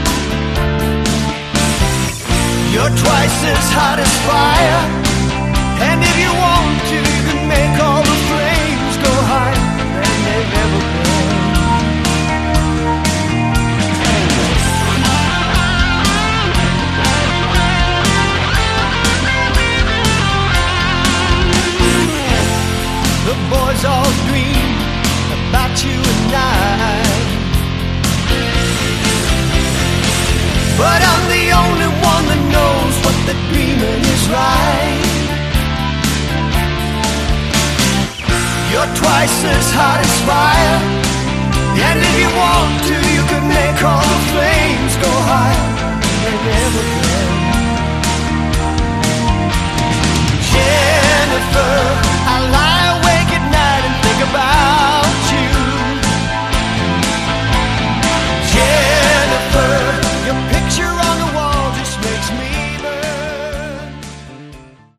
Category: AOR/melod hard rock
Vocals, Guitars
Vocals, Keyboards
Bass
Drums